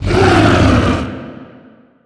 horror
Dragon Growl